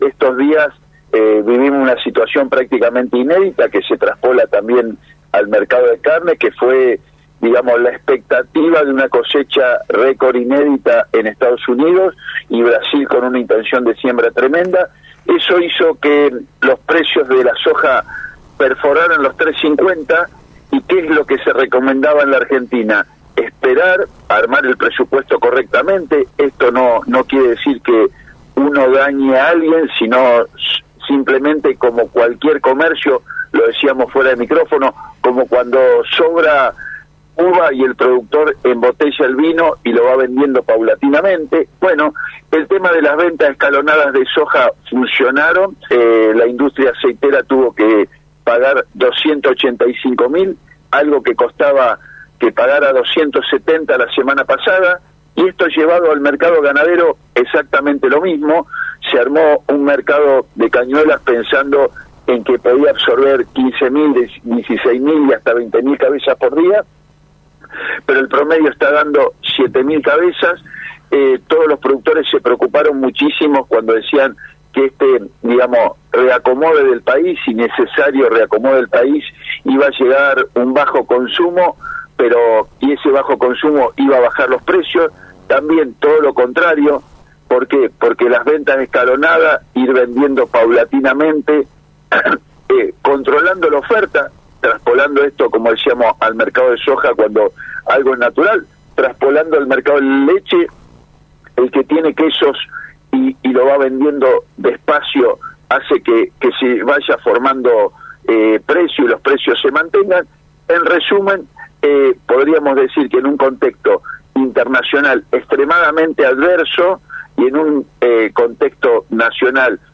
En una reciente intervención en el programa «Ahora El Campo» de radio Mon Pergamino,